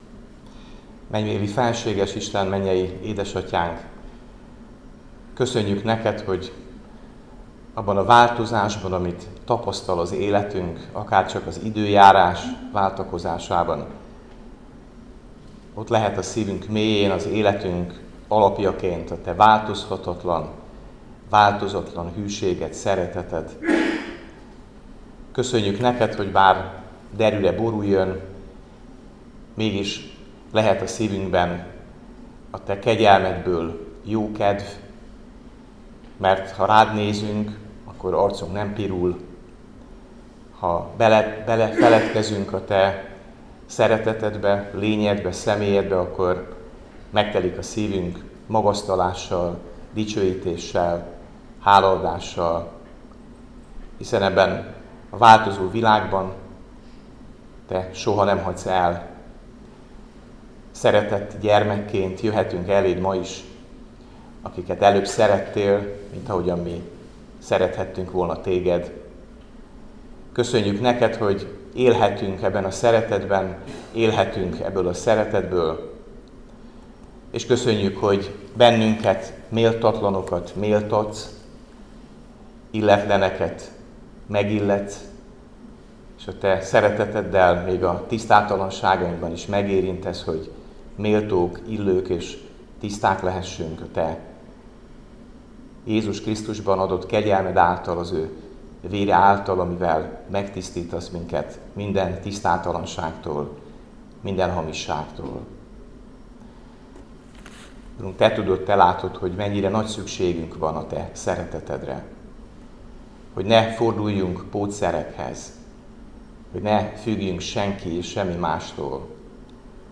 Áhítat, 2024. április 16.